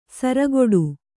♪ saragoḍu